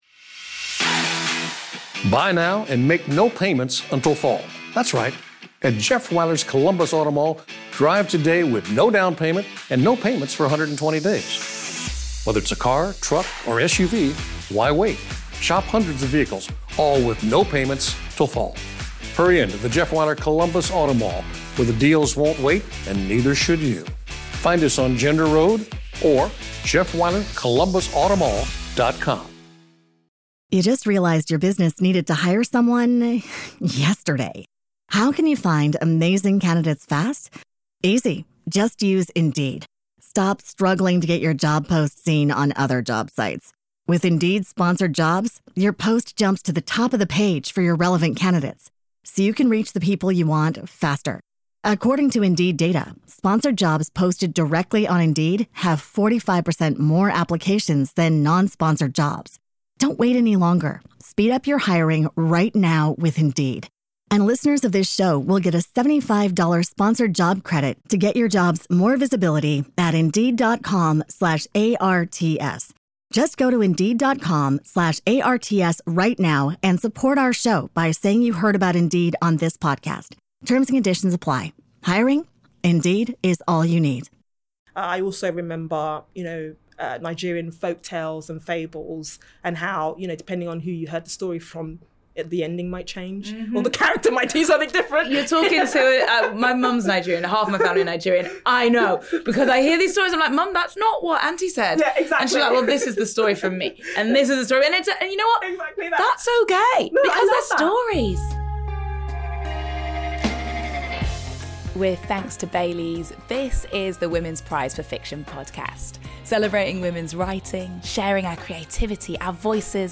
Join Vick Hope as she sits down with writer Irenosen Okojie to discuss wild imaginations and magical realism in literature and if imposter syndrome comes into play when you're a judge for the Women's Prize for Fiction.
Vick Hope, multi-award winning TV and BBC Radio 1 presenter, author and journalist, is the host of season six of the Women’s Prize for Fiction Podcast.